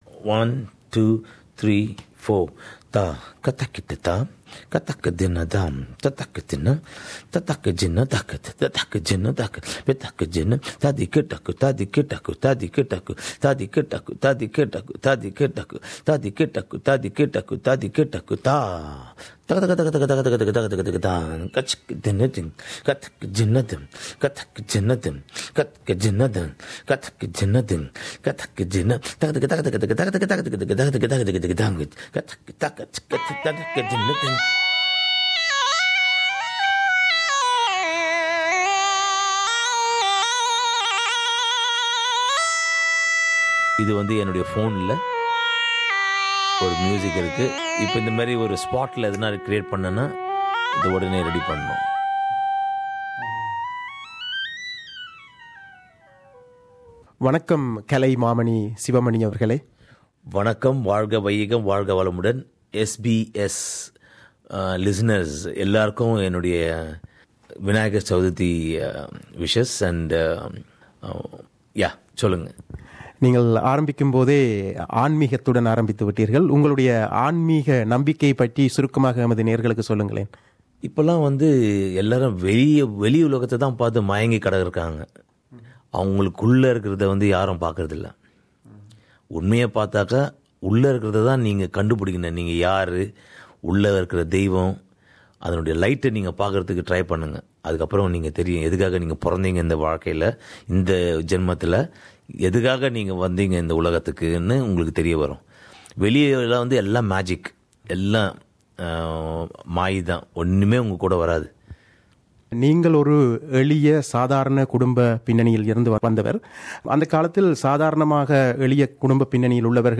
நேர்முக ஒழுங்கு